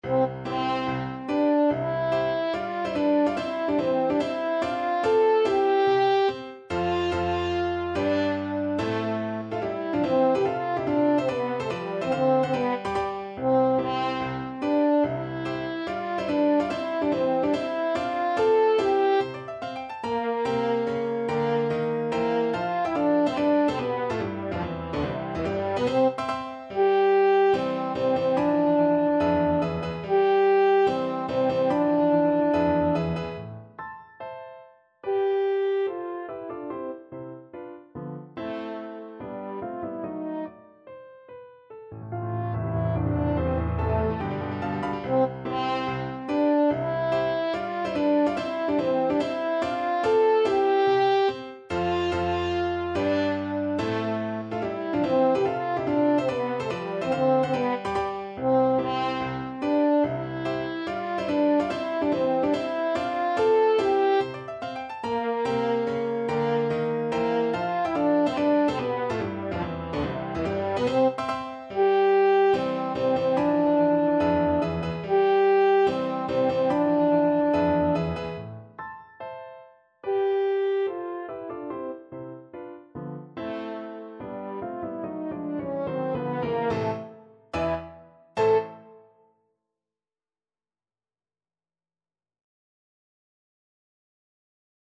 French Horn version
4/4 (View more 4/4 Music)
Allegro non troppo (=72) (View more music marked Allegro)
C4-A5
Classical (View more Classical French Horn Music)